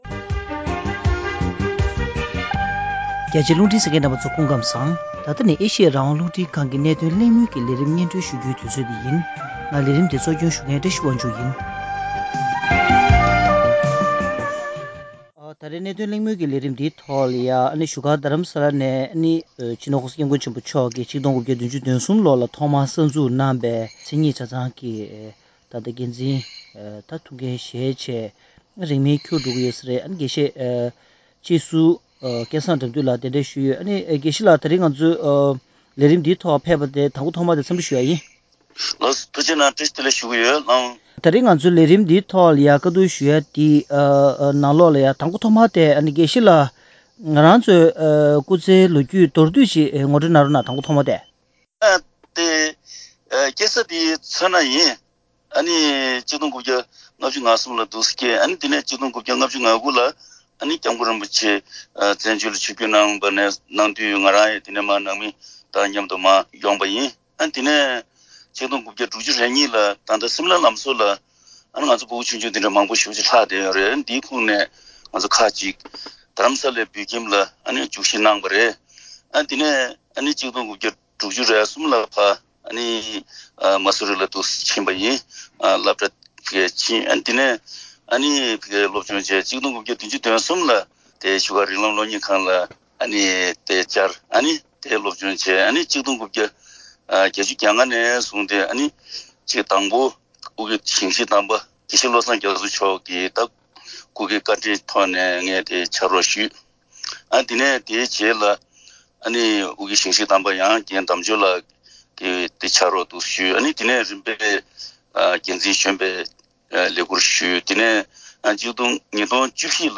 ༄༅། །གནད་དོན་གླེང་མོལ་གྱི་ལས་རིམ་ནང་།